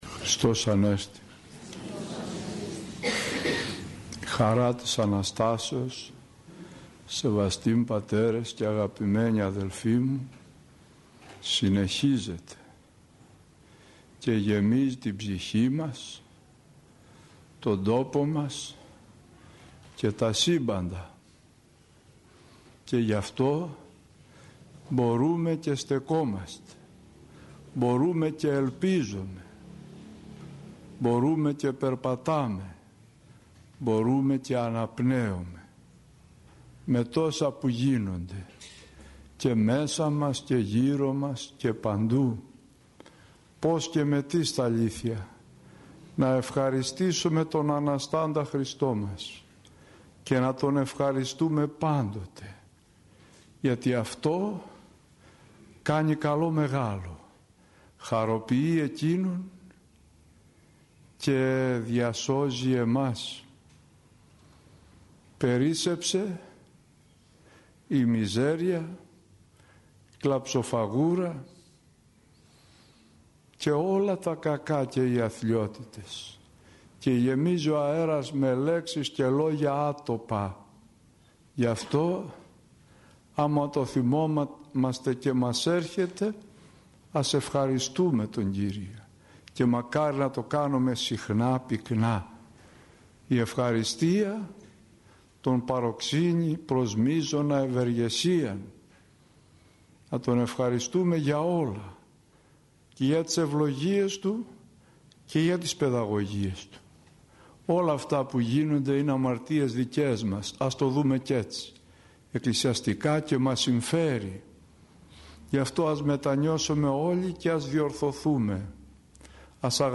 Ο Αναστημένος Χριστός μας – ηχογραφημένη ομιλία
Η ομιλία αυτή έγινε στο ίδρυμα “Άγιος Νεκτάριος”, οδός Ισαύρων 39, στα Εξάρχεια των Αθηνών.